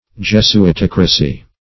Meaning of jesuitocracy. jesuitocracy synonyms, pronunciation, spelling and more from Free Dictionary.
Search Result for " jesuitocracy" : The Collaborative International Dictionary of English v.0.48: Jesuitocracy \Jes`u*it*oc"ra*cy\, n. [Jesuit + -cracy, as in aristocracy.]